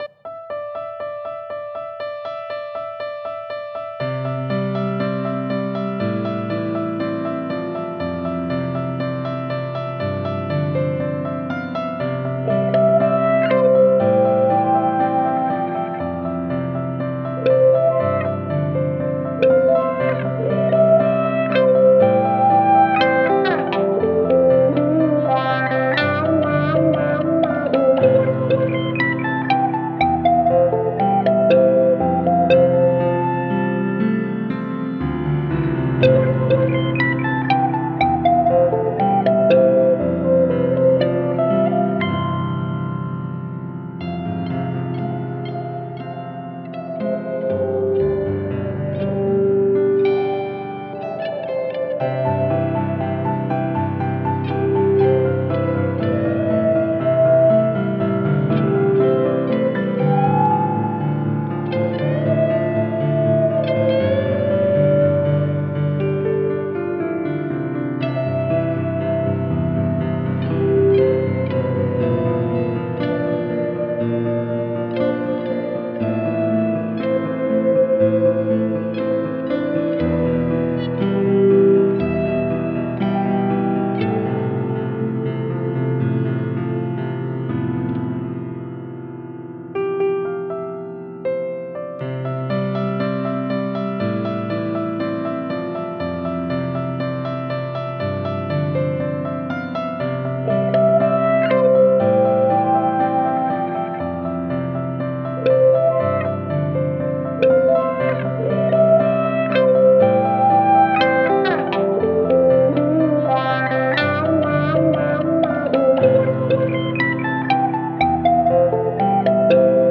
guitar
wahwah